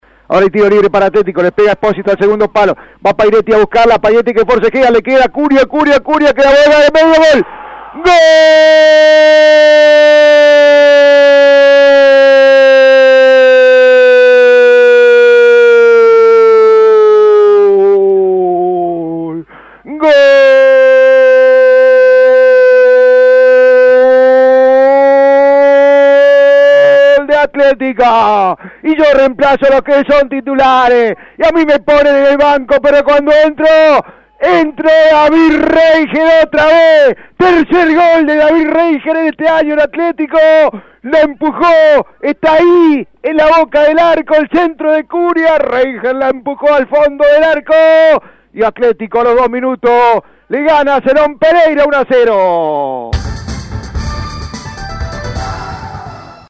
Fue transmisión central de Cadena de Transmisiones